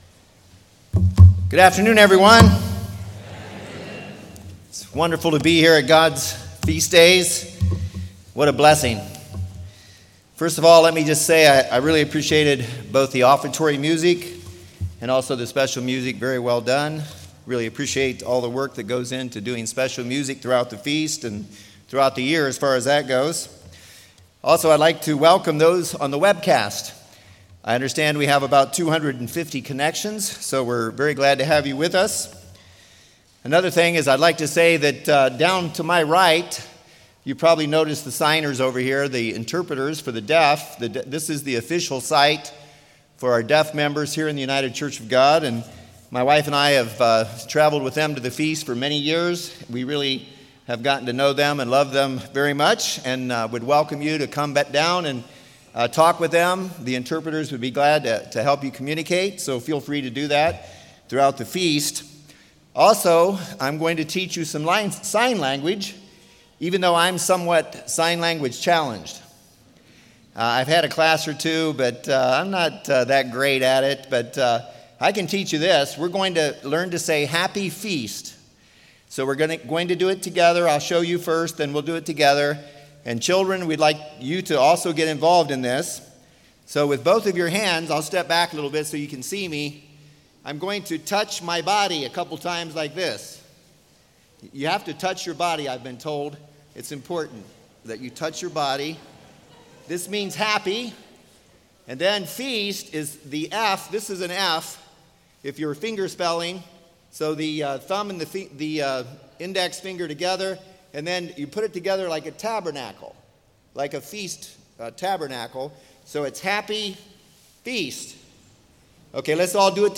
This sermon was given at the St. George, Utah 2022 Feast site.